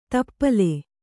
♪ tappale